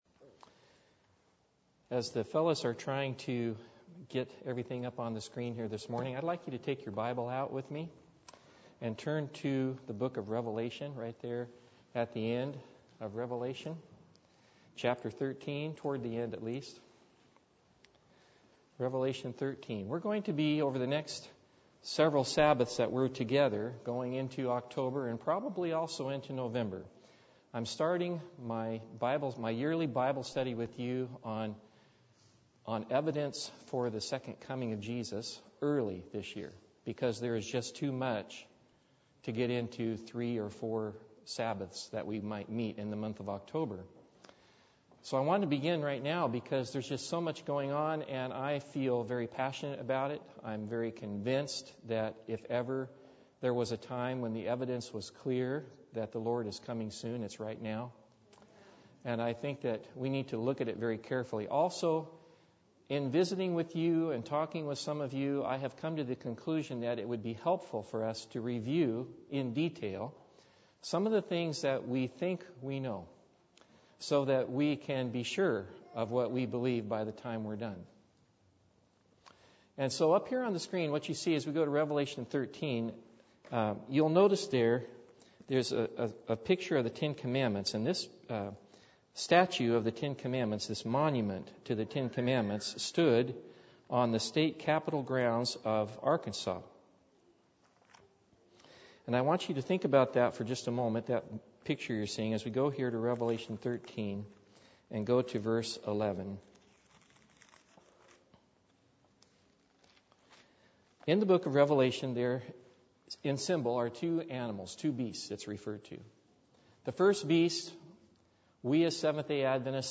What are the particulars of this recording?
Service Type: Sabbath